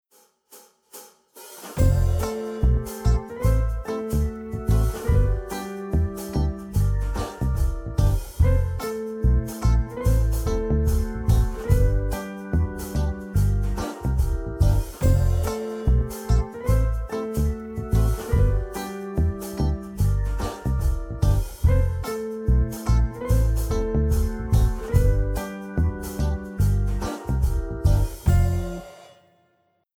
Akkordprogression med modulation og gehørsimprovisation:
Lyt efter bassen, der ofte spiller grundtonen.
Modulation: En stor sekund op eller en stor sekund ned
C instrument (demo)